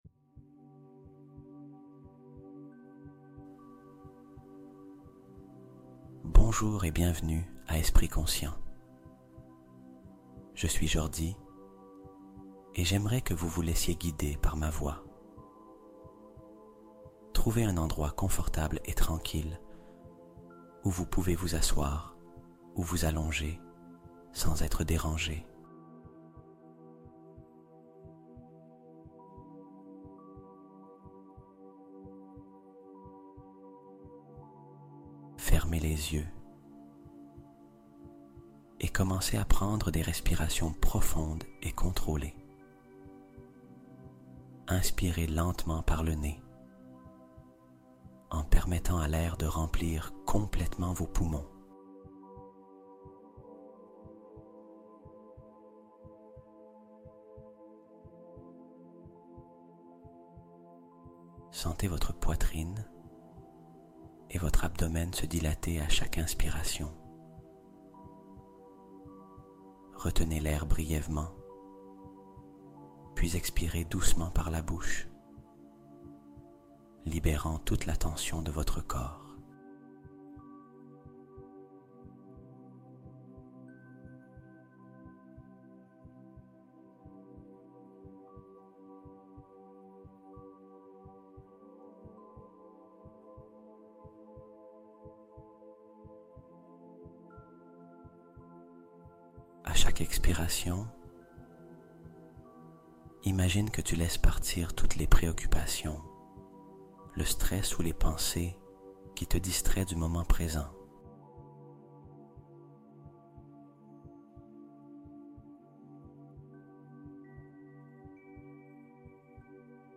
CETTE NUIT, TU RENCONTRES L'UNIVERS | Méditation Cosmique Pour Atteindre L'Harmonie Absolue En Dormant